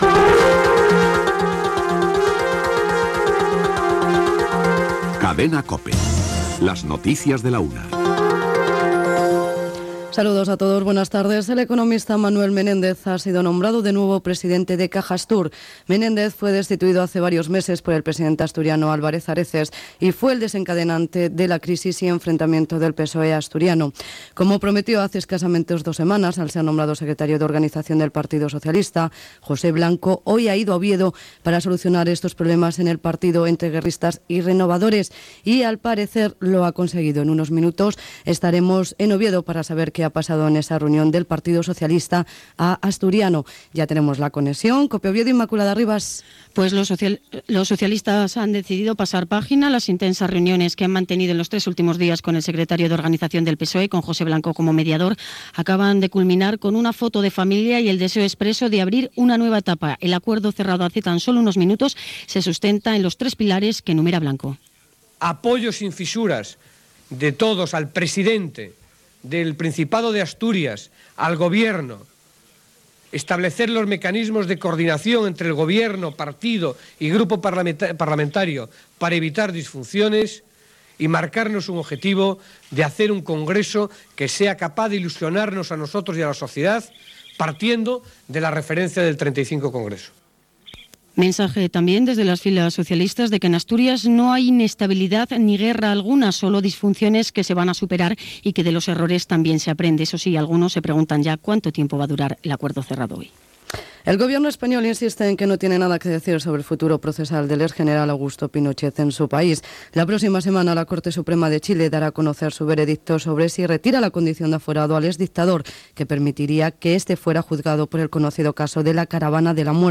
Careta del programa, Caja Astur, Partido Socialista de Asturias, Agusto Pinochet, dades de l'atur, reforma de la Llei d'estrangeria, etc. Careta de sortida, promoció del programa "Mediterráneo", publicitat, sintonia de la cadena, indicatiu, publicitat
Informatiu